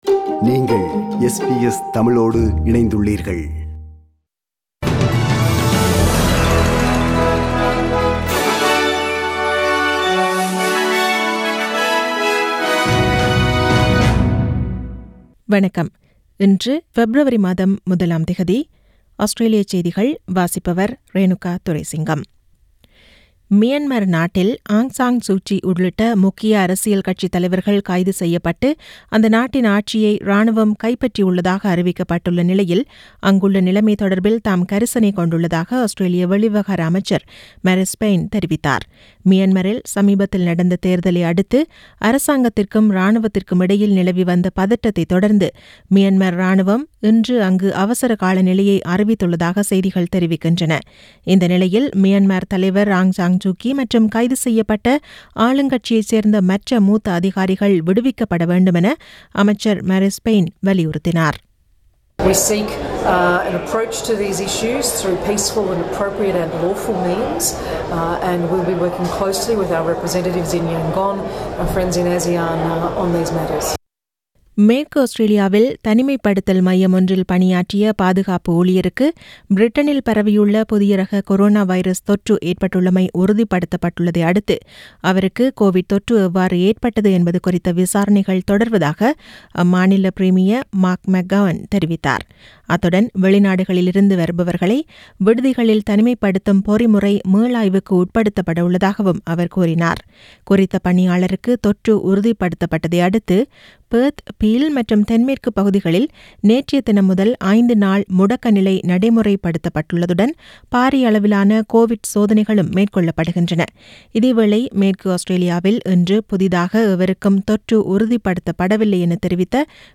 Australian news bulletin for Monday (01 Feb 2021).